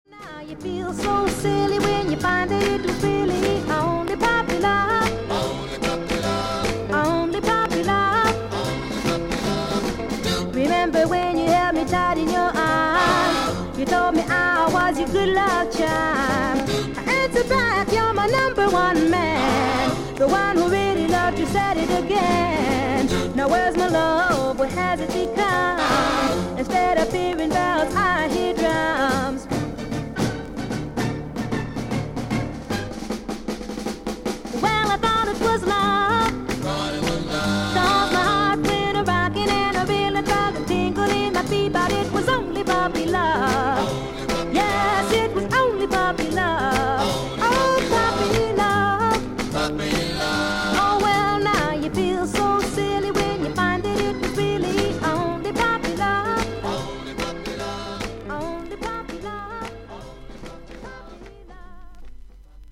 30秒間少々軽い周回ノイズがあります。
ほかはVG++〜VG+:少々軽いパチノイズの箇所あり。クリアな音です。
女性R&B/ソウル・シンガー。